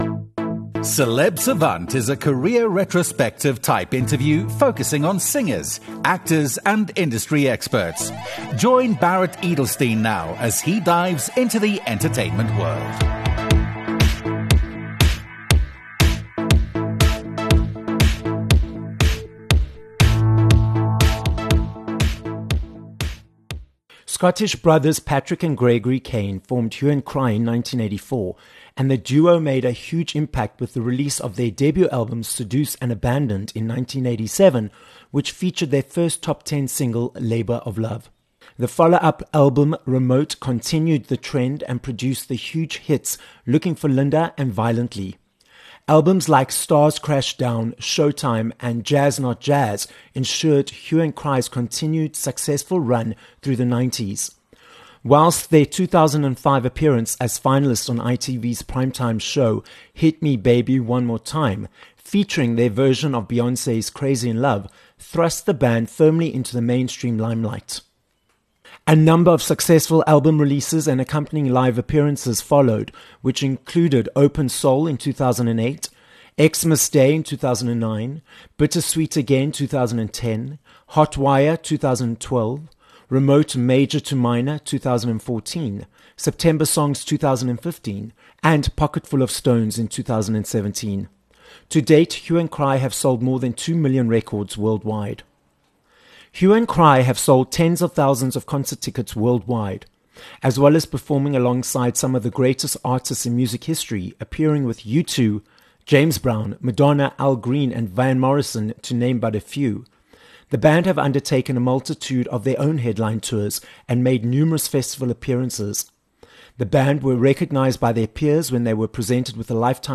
25 Apr Interview with Hue and Cry